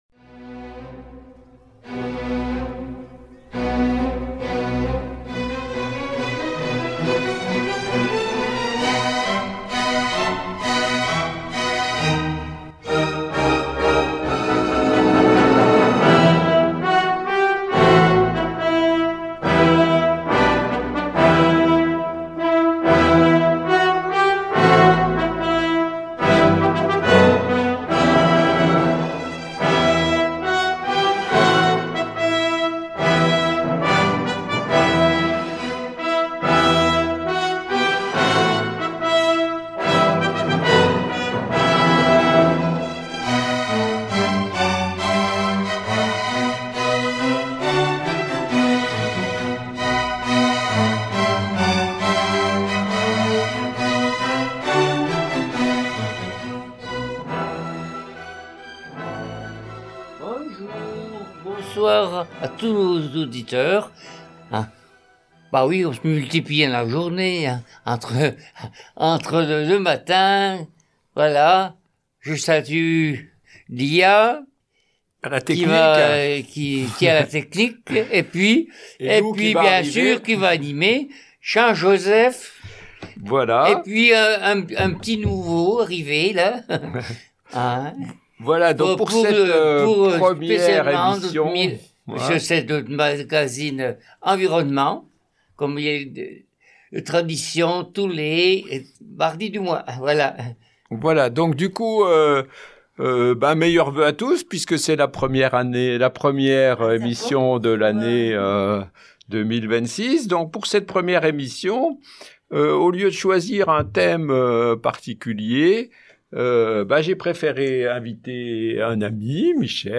Comment penser notre place sur la Terre aujourd’hui ? Pour ouvrir le dossier environnement 2026, La Parole est à vouspropose une émission de réflexion collective.